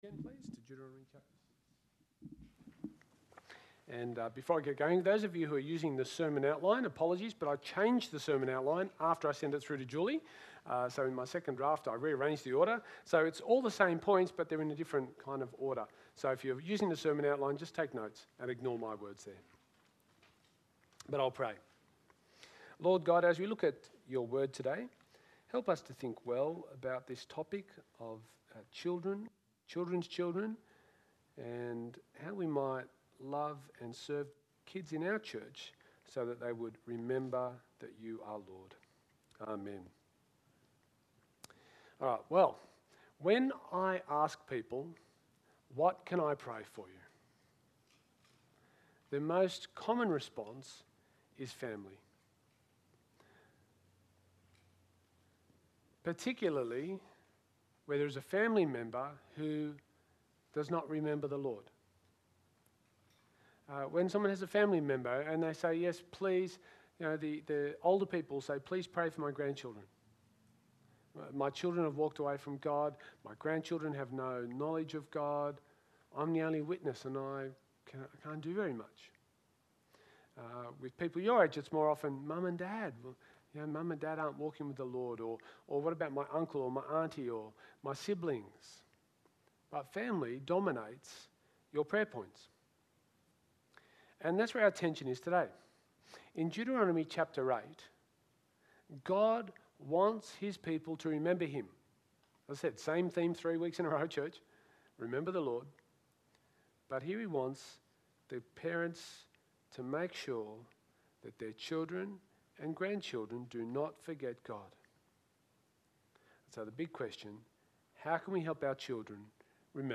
SERMON – Remember the Lord by Passing on the Faith